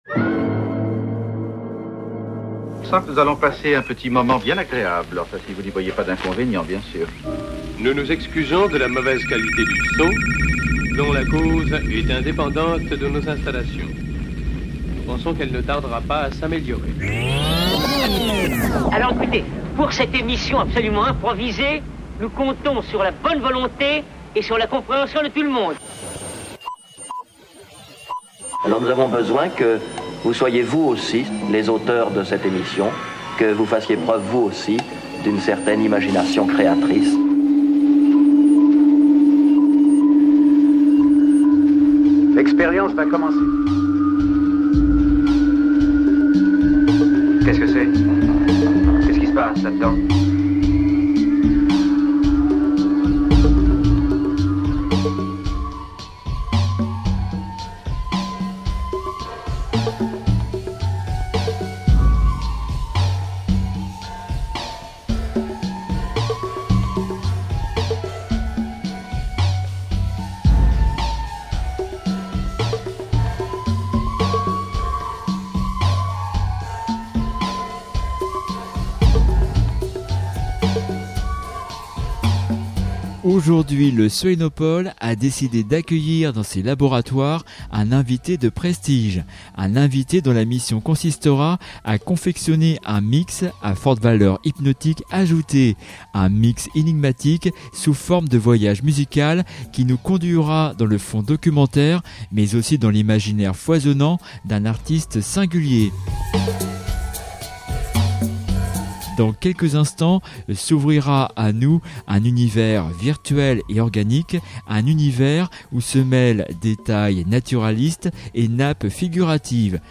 Exclusive radio mix
un mix à forte valeur hypnotique ajoutée